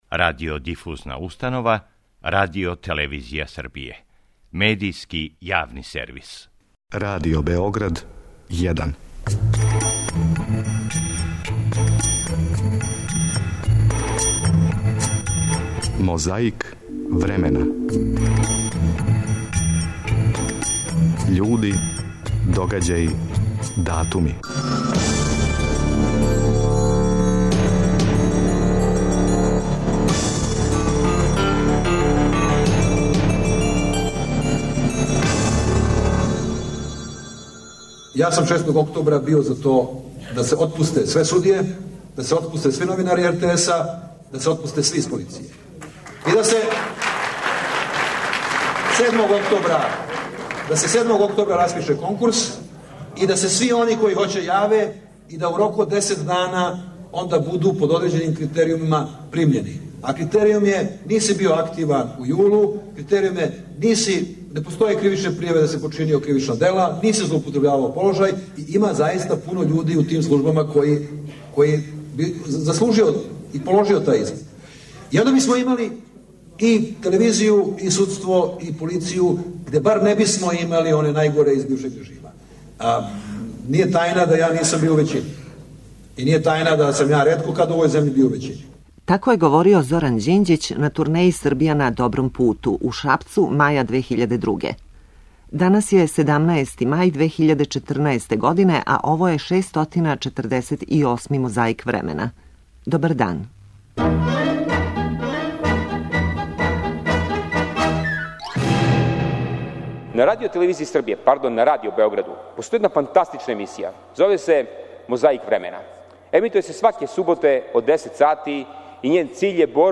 Враћамо се у мај 2002 године да се подсетимо како је говорио Зоран Ђинђић на турнеји 'Србија на добром путу' у Шапцу.
Место - Студеница. Датум - 18. мај 1986. Говорник - Патријарх српски Герман.
Звучна коцкица подсећа како је радио извештавао.